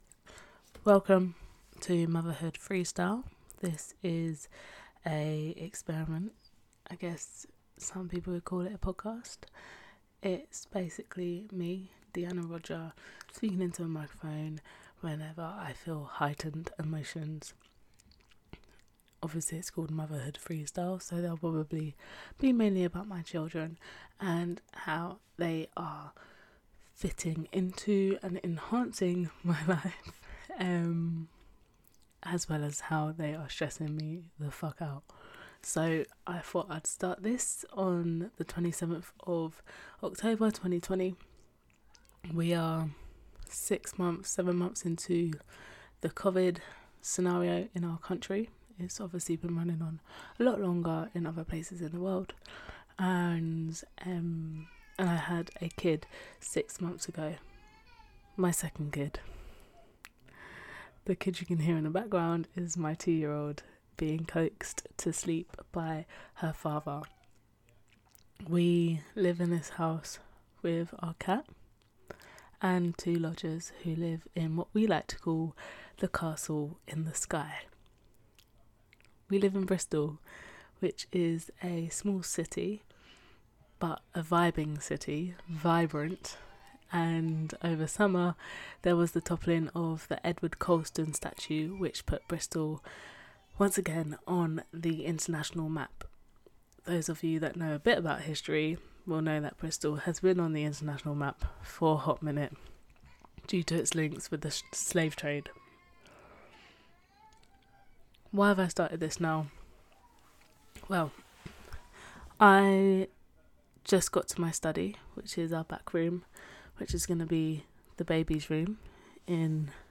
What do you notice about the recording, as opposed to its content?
Basically I want to write more than I currently can and so ofen ramble ideas/rants into my phone.